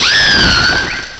cry_not_serperior.aif